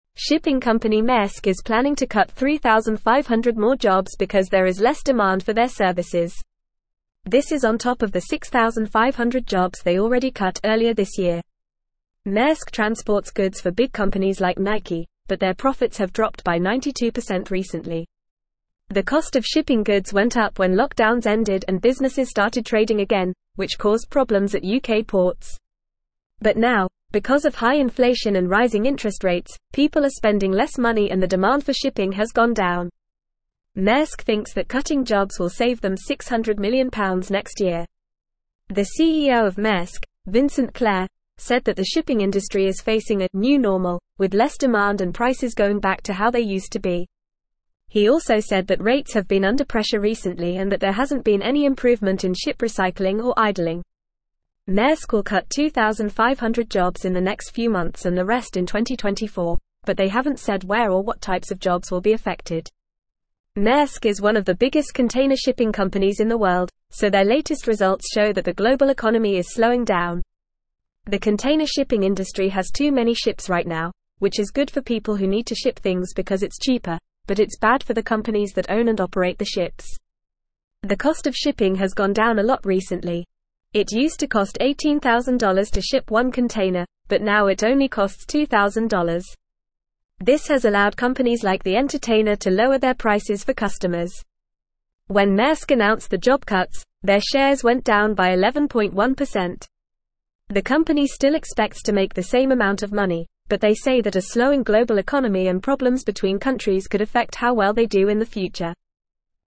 Fast
English-Newsroom-Upper-Intermediate-FAST-Reading-Maersk-to-Cut-3500-Jobs-Amid-Lower-Demand.mp3